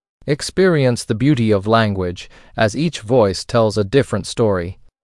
en-male-1.mp3